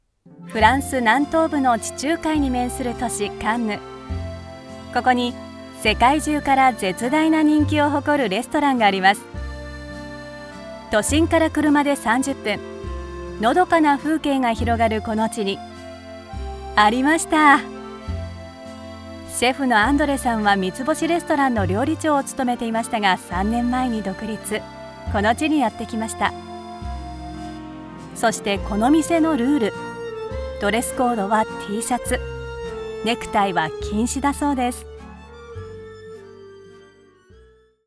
If you’re looking for a voiceover that is based in japan i have many years of experience, and i can help you with your next project.
francecanneBGM.wav